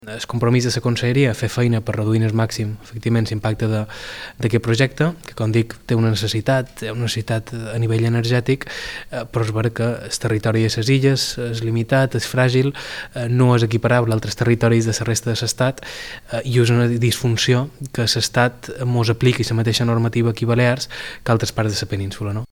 Tot i això, el director general d’Energia, Joan Groizard, ha assegurat a IB3 Ràdio que lluitaran per reduir al màxim l’impacte ambiental de l’avantprojecte que recull la instal·lació de torres elèctriques de 57 metres d’alçada.